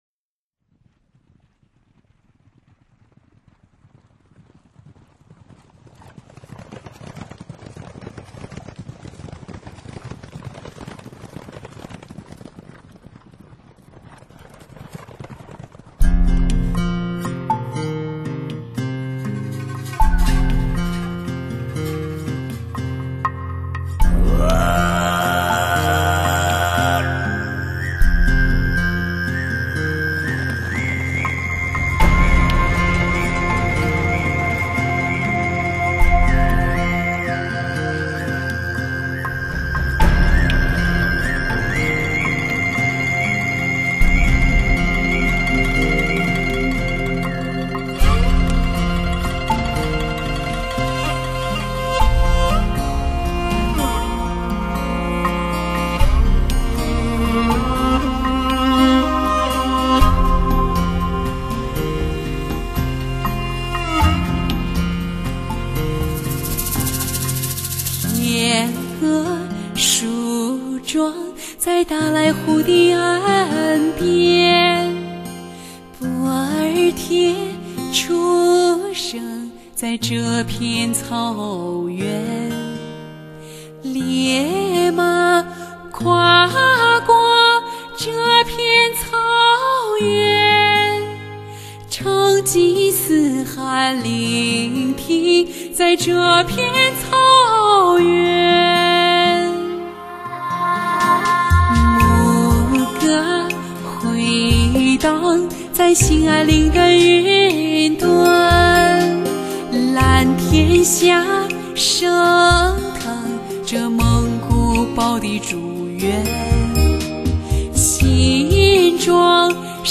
未雕琢天成“自然立体派唱法”，原音草原歌网王牌点击，少有原生蒙藏歌风！